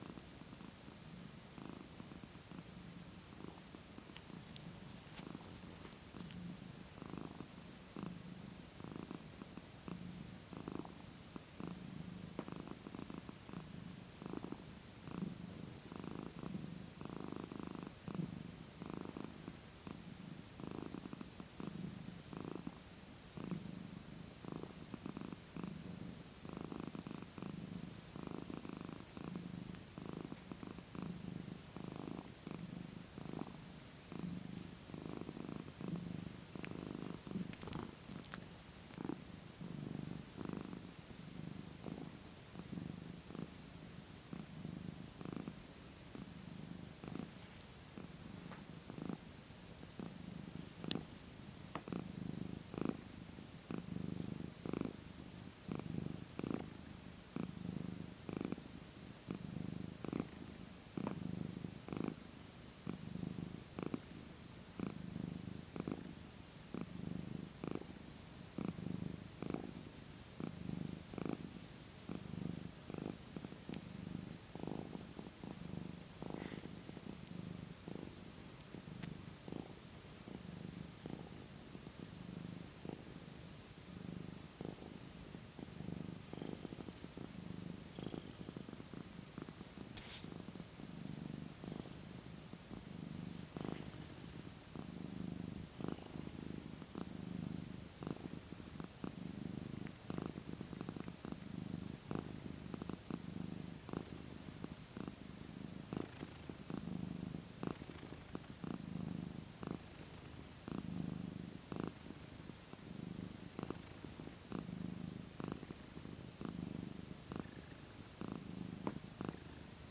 What a Purr-fect Morning. <3..
Perfect noise to wake up next to.